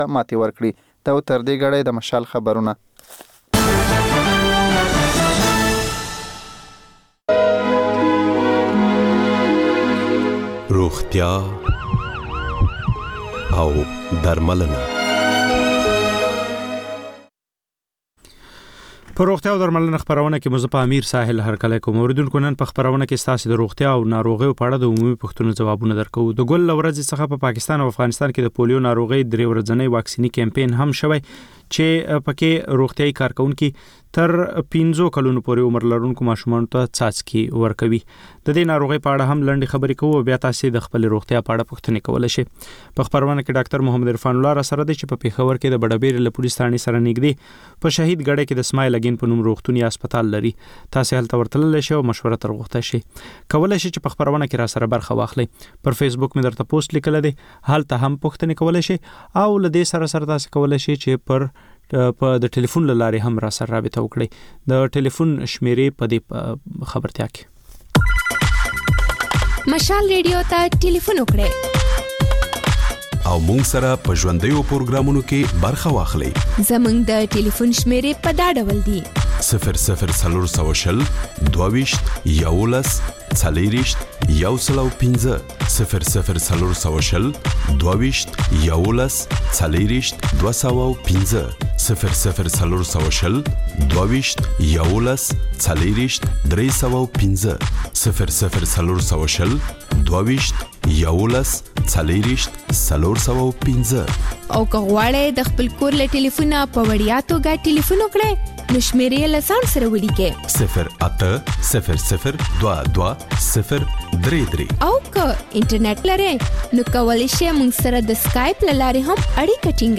په روغتیا او درملنه خپرونه کې یو ډاکتر د یوې ځانګړې ناروغۍ په اړه د خلکو پوښتنو ته د ټیلي فون له لارې ځواب وايي.